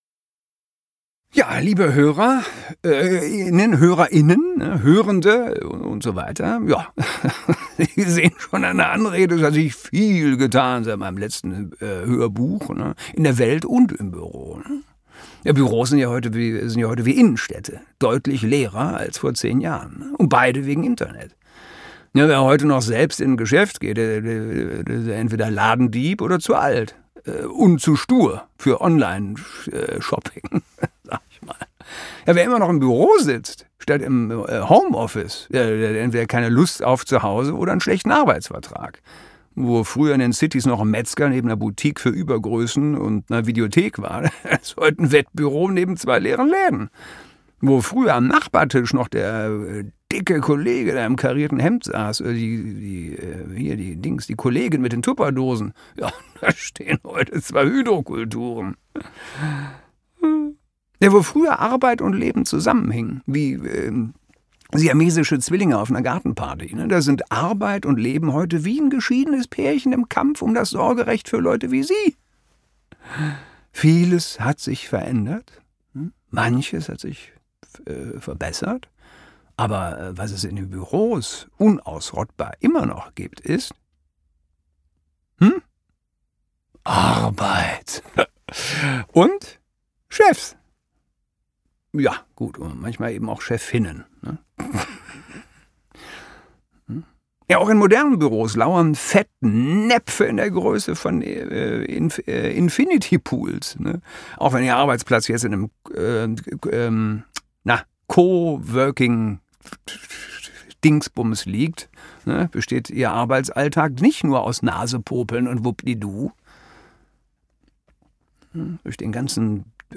Gelesen von Christoph Maria Herbst, Bjarne Mädel, Oliver Wnuk, Diana Staehly, Milena Dreißig und Ralf Husmann.